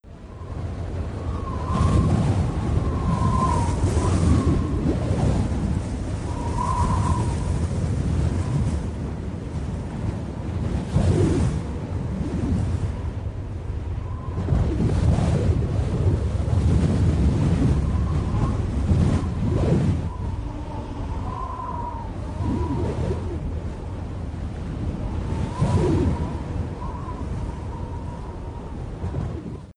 Wind-Heavy.wav